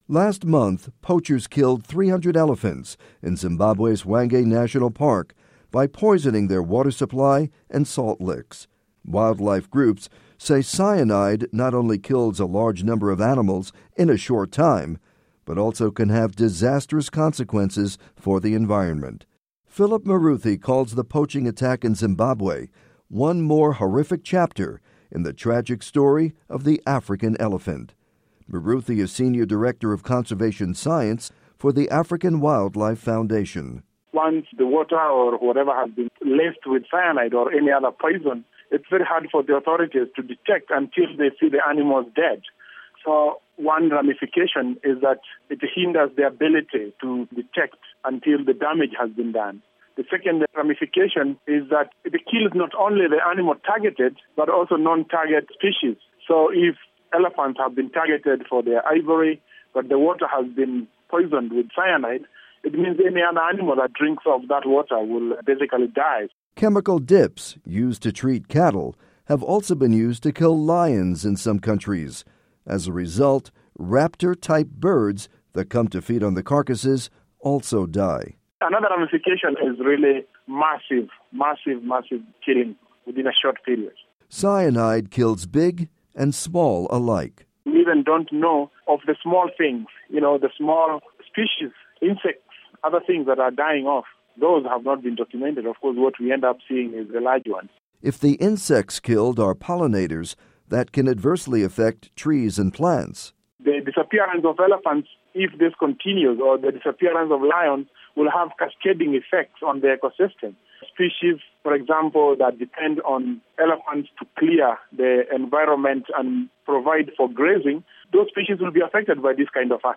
report on elephant poisonings